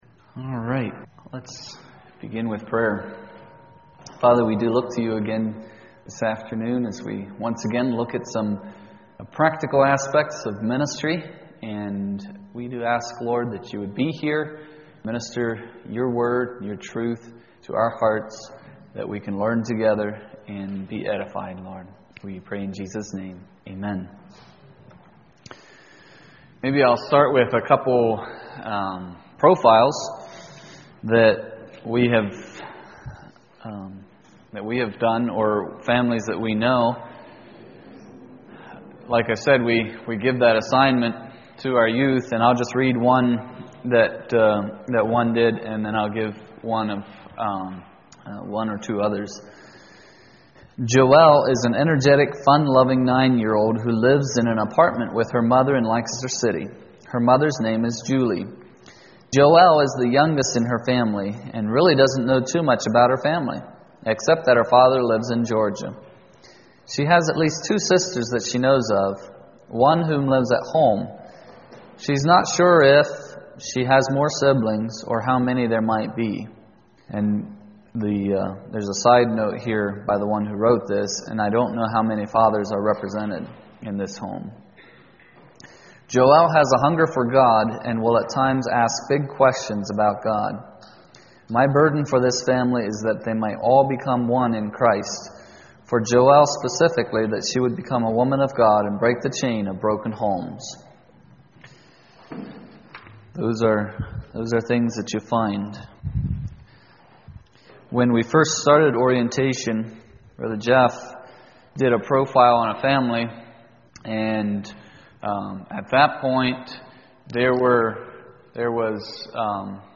Reach 10.14 Kid's Ministry Seminar Service Type: Saturday Evening %todo_render% « Teaching